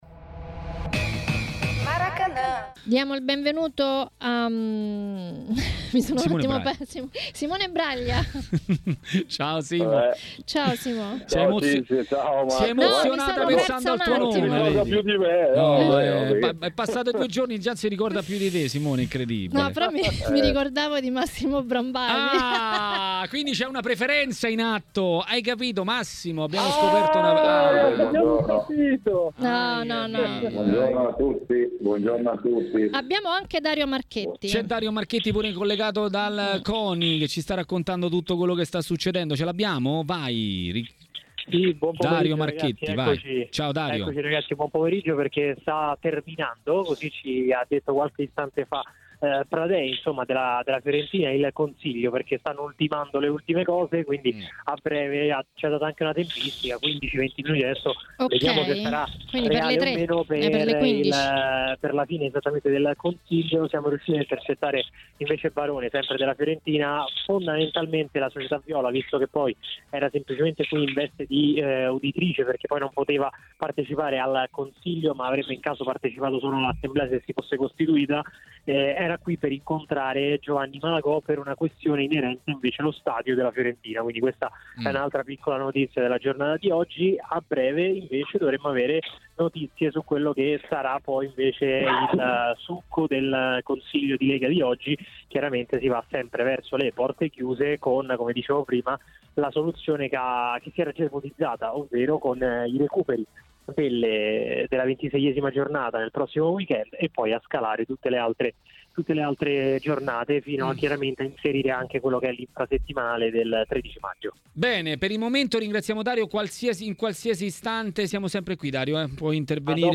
L'ex portiere Simone Braglia è intervenuto per parlare di Milan a TMW Radio, durante Maracanà.